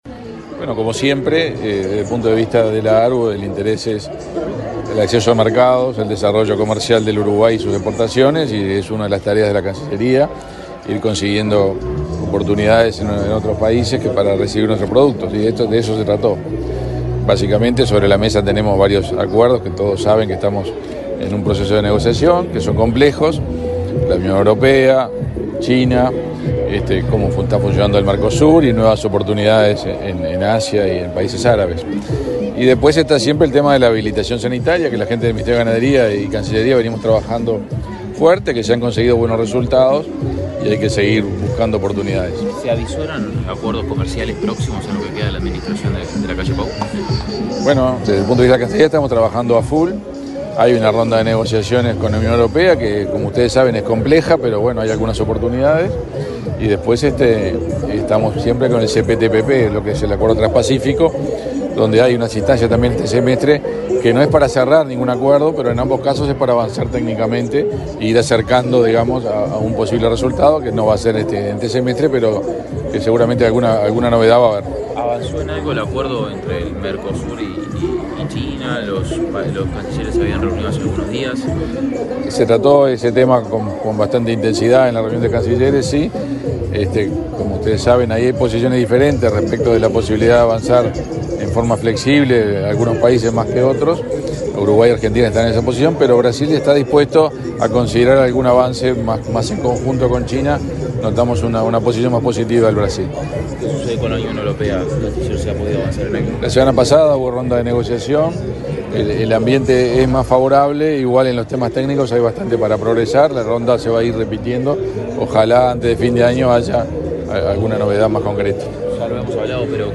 Declaraciones del canciller, Omar Paganini
Declaraciones del canciller, Omar Paganini 12/09/2024 Compartir Facebook X Copiar enlace WhatsApp LinkedIn El canciller de la República, Omar Paganini, dialogó con la prensa, luego de participar en una reunión con directivos de la Asociación Rural del Uruguay, este jueves 12 en la Expo Prado 2024.